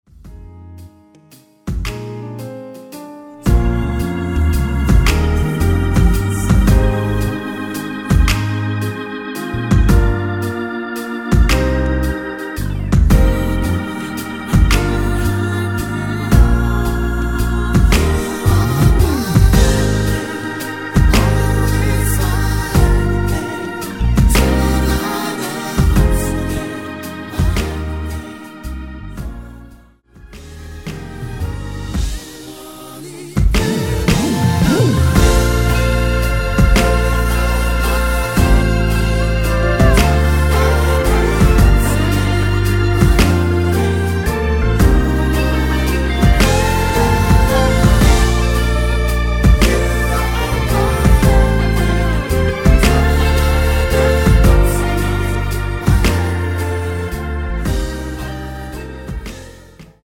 원키 코러스 포함된 MR 입니다.(미리듣기 참조)
Eb